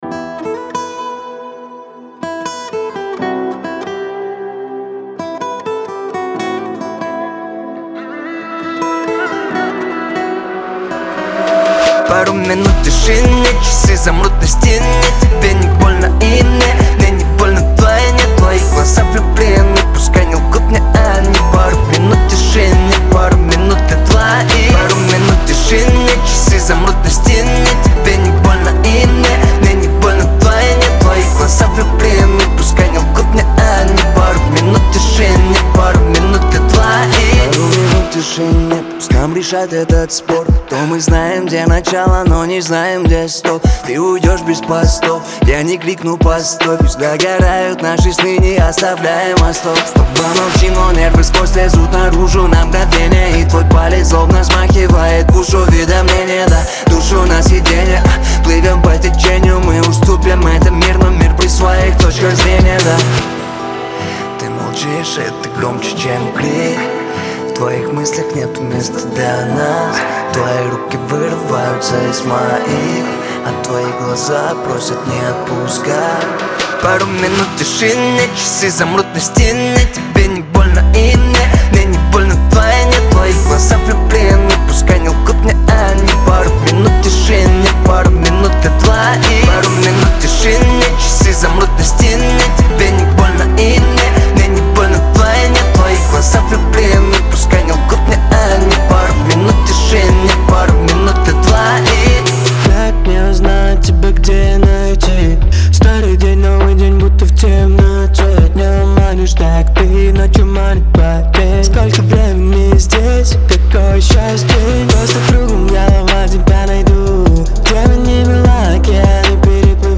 который сочетает в себе элементы электронной и поп-музыки.